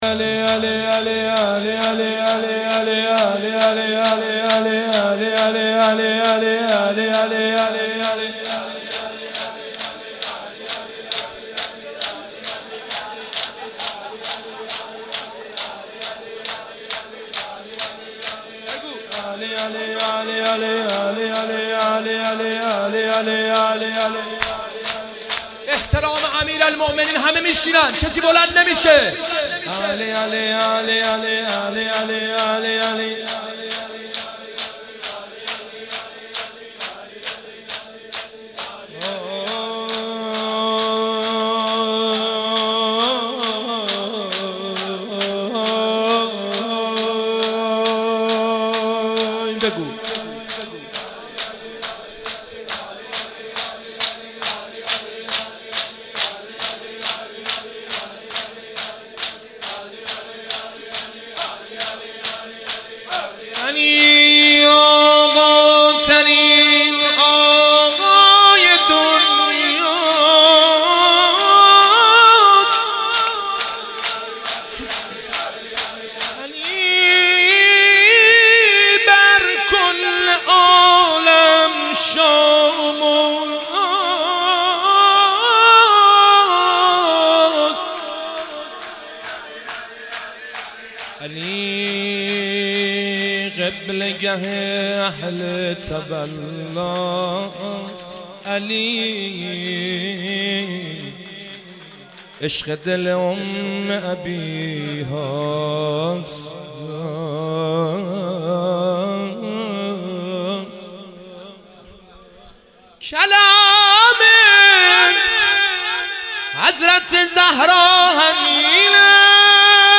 قالب : شعر خوانی